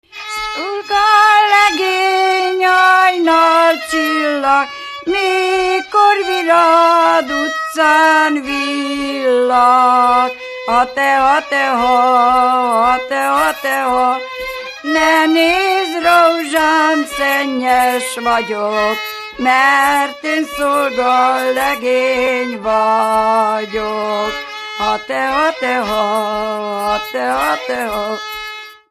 Erdély - Beszterce-Naszód vm. - Zselyk
), ének
hegedű
Műfaj: Lakodalmas
Stílus: 4. Sirató stílusú dallamok
Kadencia: 4 (b3) 1 1